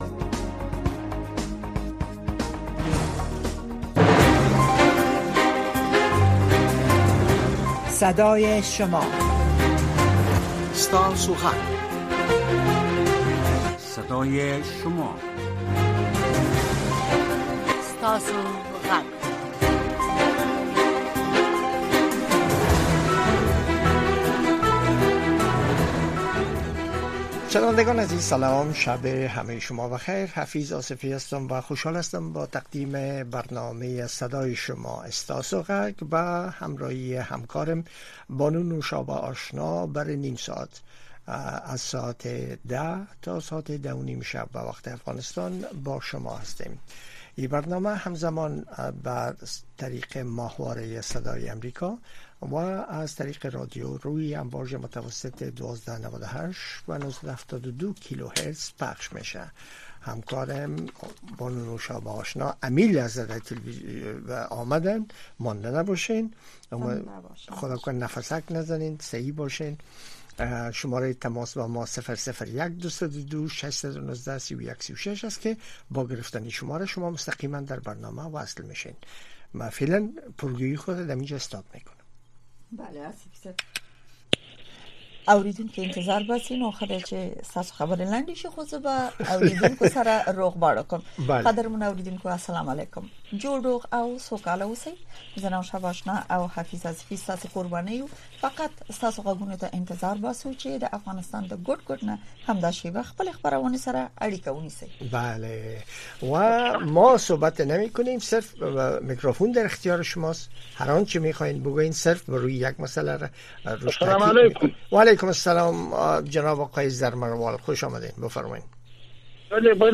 این برنامه به گونۀ زنده از ساعت ۱۰:۰۰ تا ۱۰:۳۰ شب به وقت افغانستان نشر می‌شود.